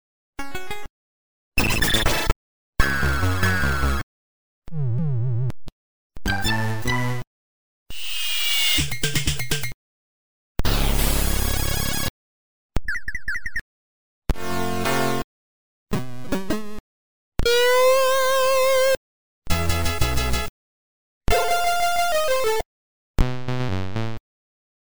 Insgesamt sind es 14 titel.